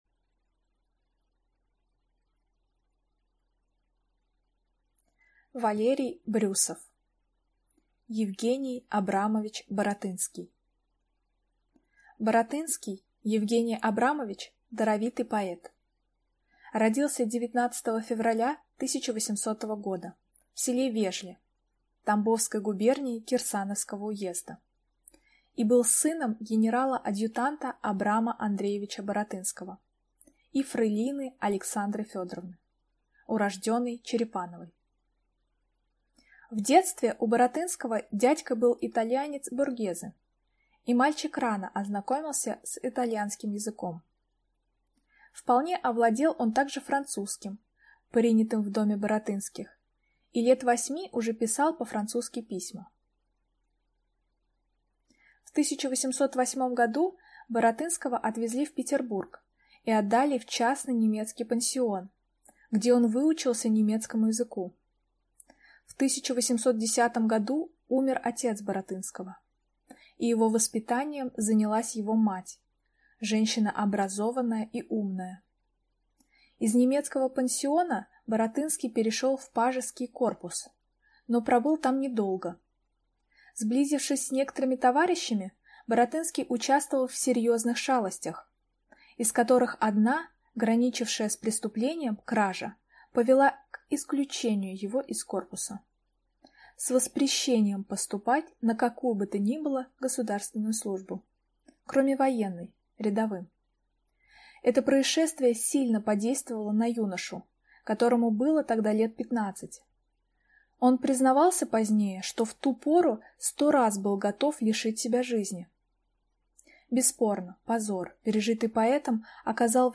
Аудиокнига Е. А. Баратынский | Библиотека аудиокниг